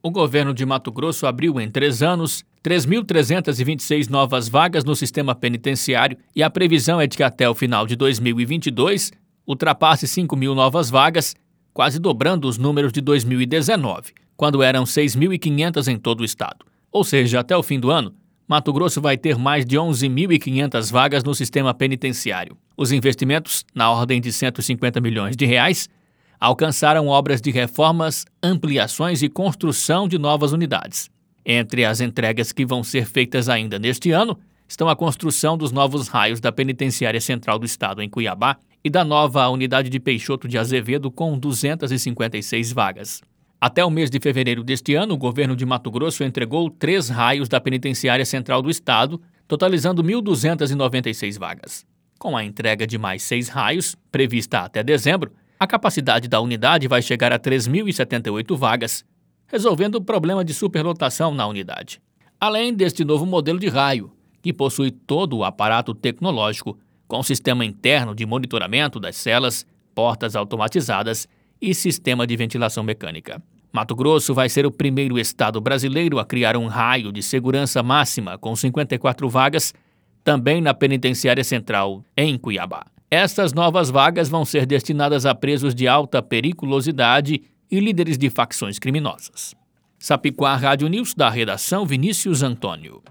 Boletins de MT 07 mar, 2022